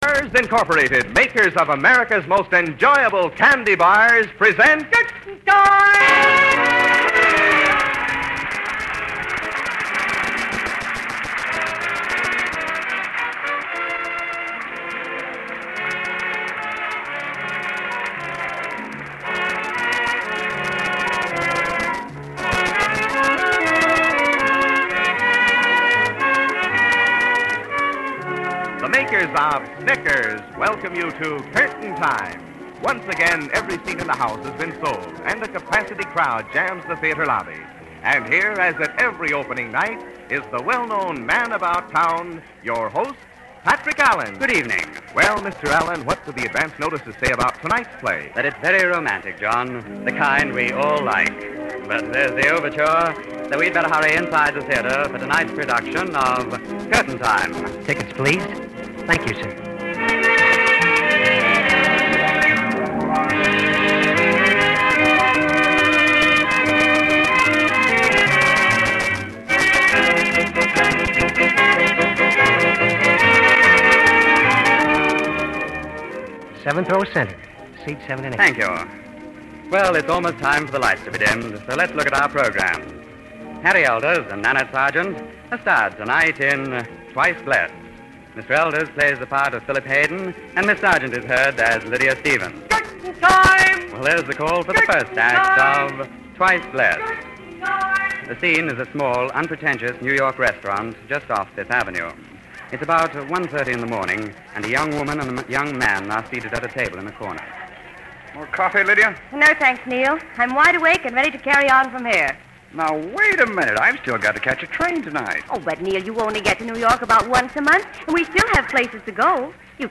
Curtain Time was a popular American radio anthology program that aired during the Golden Age of Radio.
Format and Features: "Theater Atmosphere": The show used sound effects and announcements to evoke the feeling of being in a theater, with an announcer acting as an usher and reminding listeners to have their tickets ready. Romantic Dramas: Each episode featured a different romantic story, often with a "boy meets girl" theme.